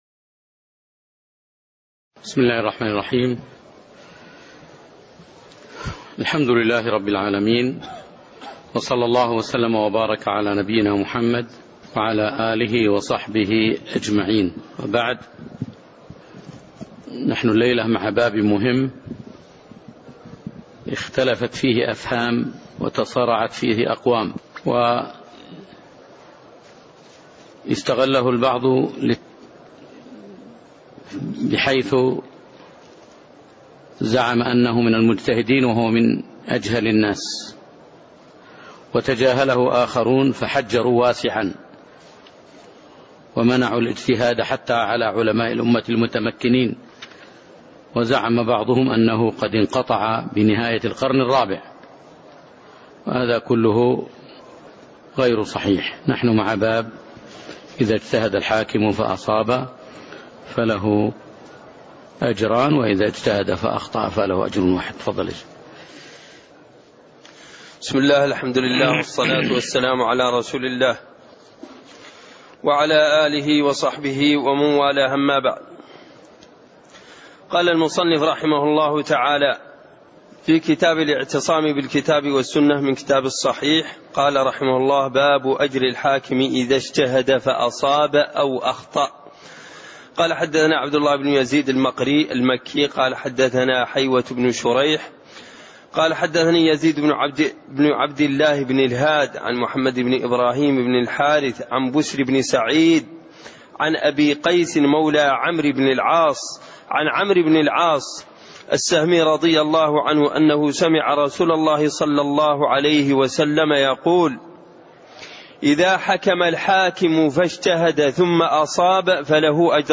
تاريخ النشر ٢١ محرم ١٤٣٢ هـ المكان: المسجد النبوي الشيخ